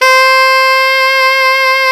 SAX ALTOFF0F.wav